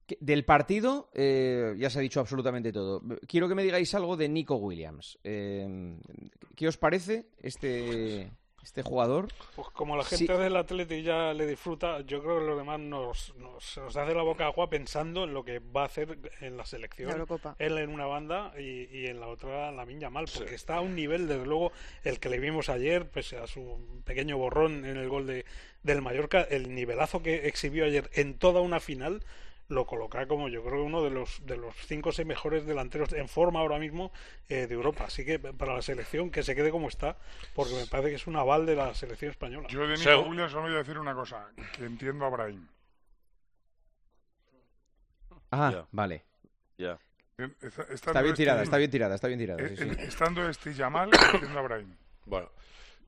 Juanma Castaño pidió a El Tertulión de Tiempo de Juego que valorasen la actuación del jugador del Athletic Club y el narrador del encuentro aprovechó el momento